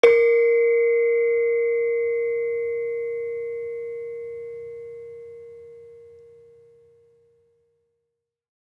Gamelan Sound Bank
Saron-3-A#3-f.wav